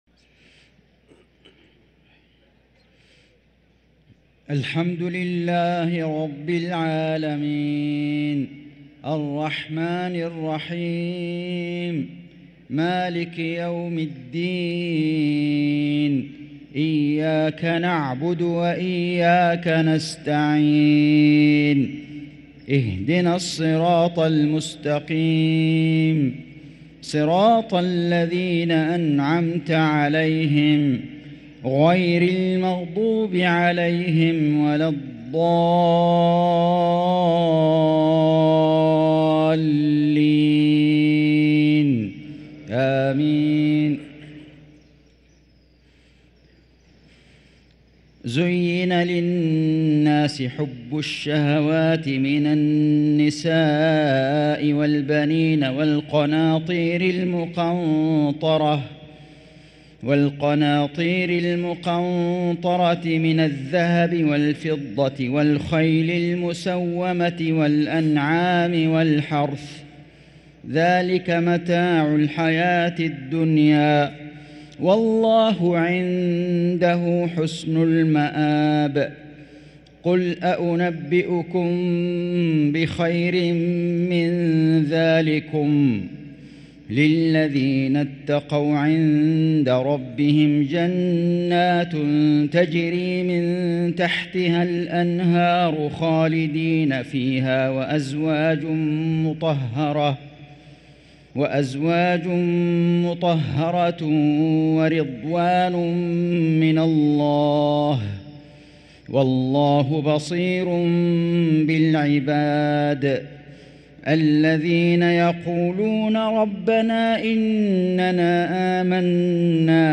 صلاة المغرب للقارئ فيصل غزاوي 8 رمضان 1444 هـ
تِلَاوَات الْحَرَمَيْن .